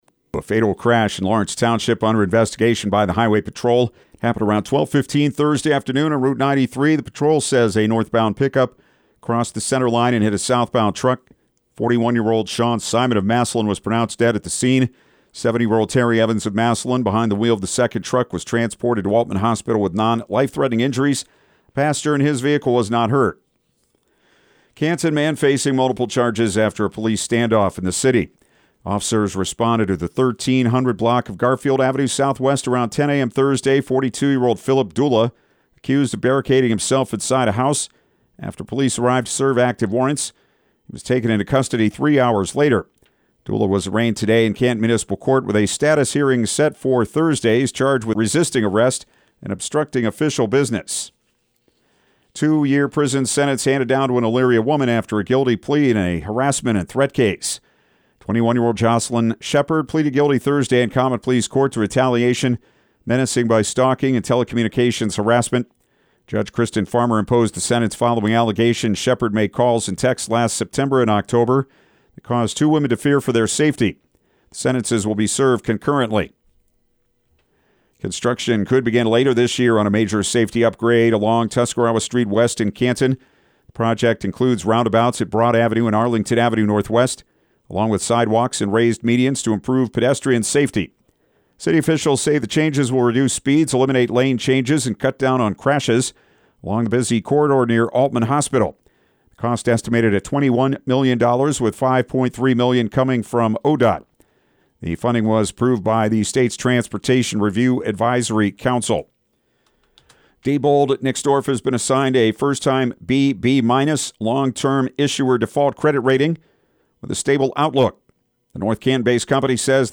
Evening News
EVENING-NEWS-24.mp3